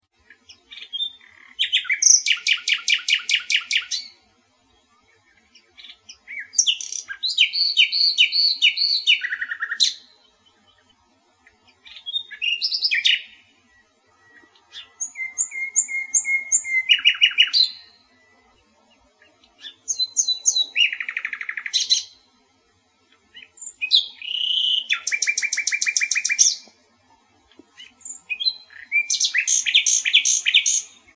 Slavík obecný (Luscinia megarhynchos)
Zpěv Slavíka Obecného
Skalka
Zpívá ve dne i v noci obvykle dobře skrytý v husté vegetaci. Má neobyčejně bohatý hlasový fond. Při svém zpěvu střídá flétnové tóny, vydává silný tlukot, hvízdá i kloktá.
slavik-obecny--luscinia-megarhynchos-.mp3